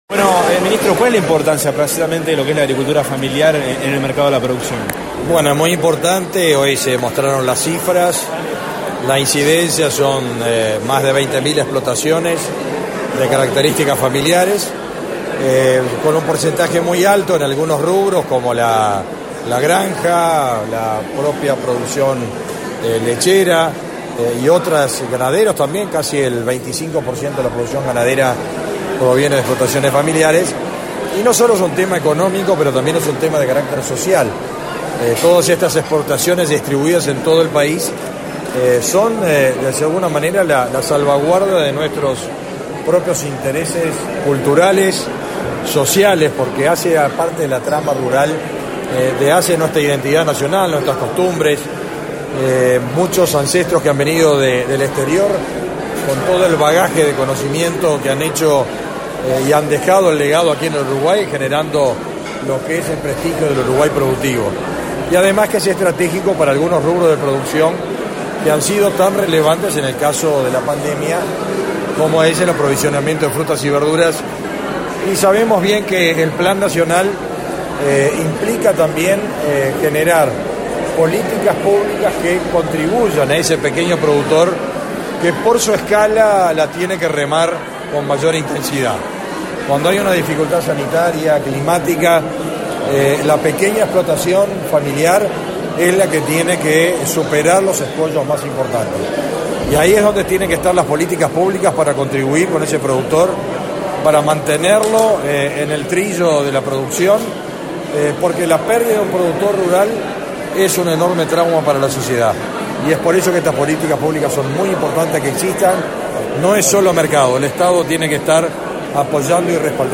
Declaraciones a la prensa del ministro de Ganadería, Agricultura y Pesca, Fernando Mattos
Tras el evento, el ministro de Ganadería, Agricultura y Pesca, Fernando Mattos, realizó declaraciones a la prensa.